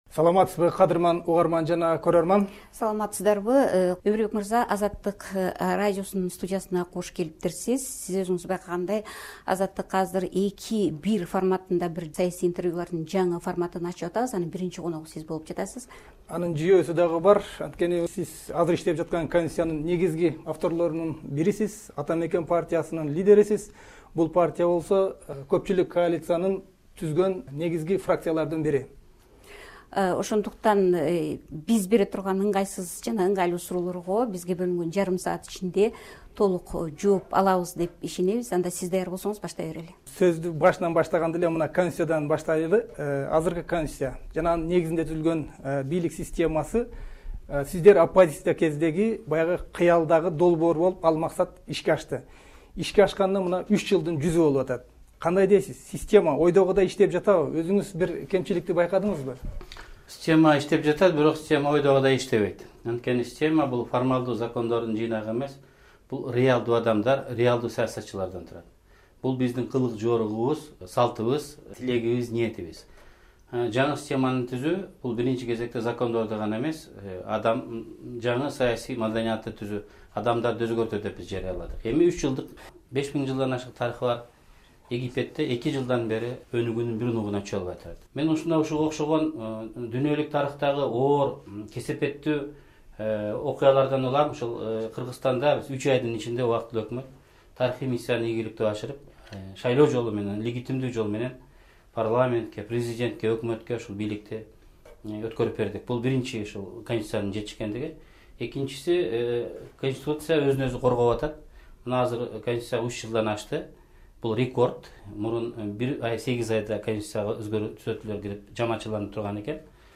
“Азаттык” саясий-экономикалык талкуунун жаңы форматын сунуштайт. Мында бир саясатчыга, же лидерге эки журналист эки жактан суроо берет.